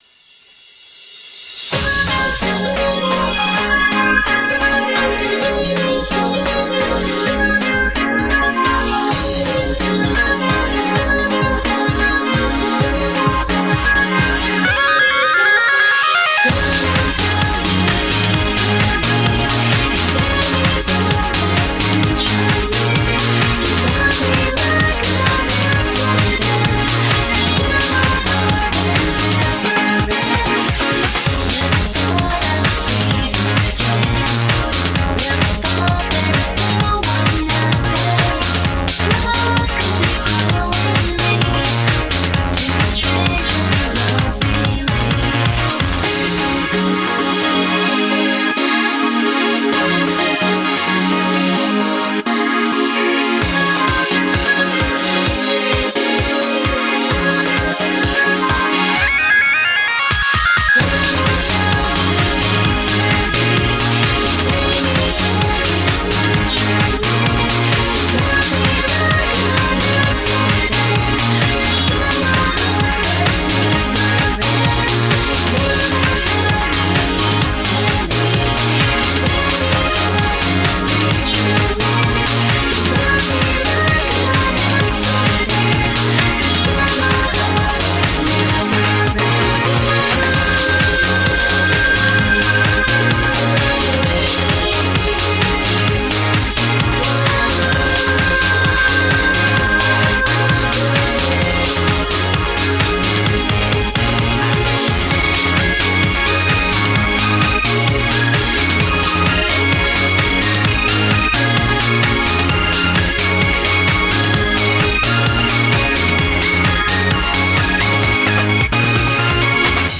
BPM130
Audio QualityLine Out